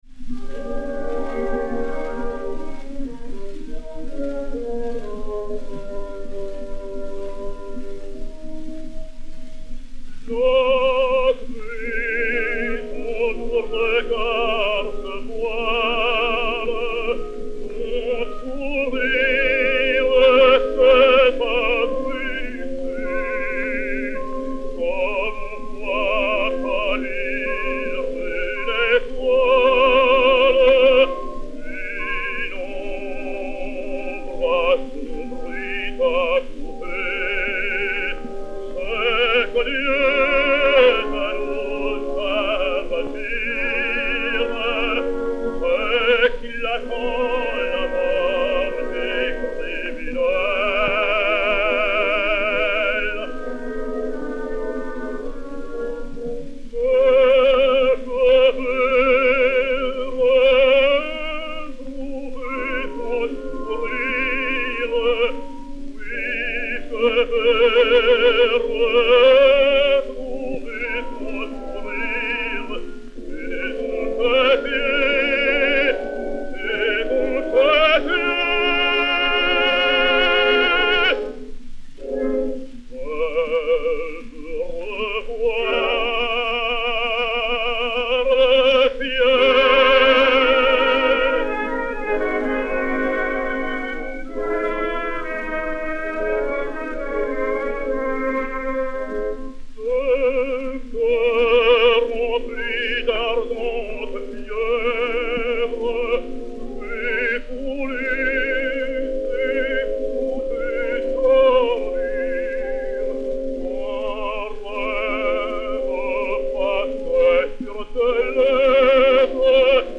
Daniel Vigneau (Nilakantha) et Orchestre